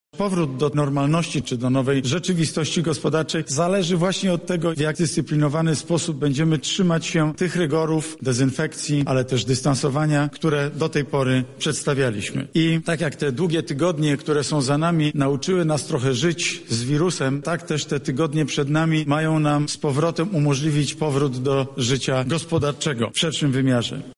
-mówi premier Mateusz Morawiecki.